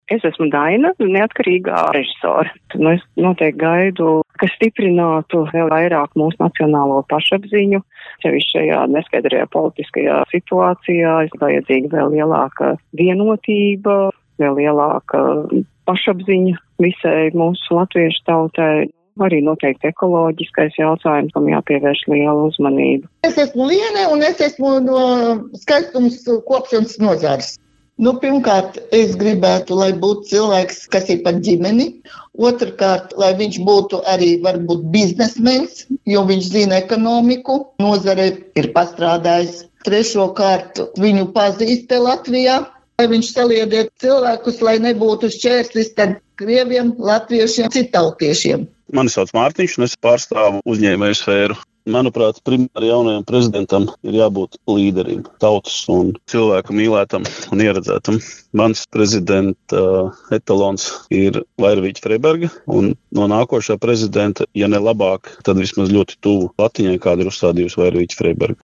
Kamēr vakar Saeima izvēlējās nākamo valsts galvu, RADIO SKONTO vairākiem Latvijas iedzīvotājiem jautāja, ko viņi sagaida no jaunā Valsts prezidenta.